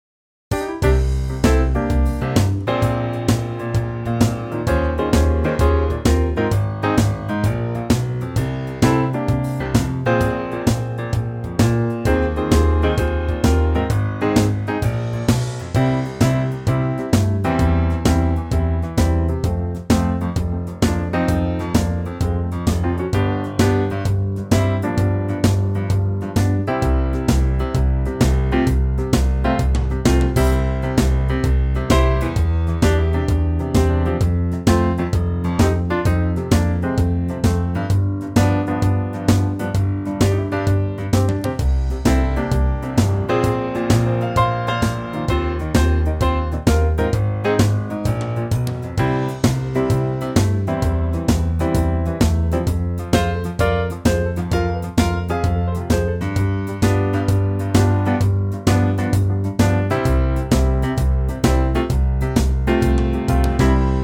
Unique Backing Tracks
key - C - vocal range - C to E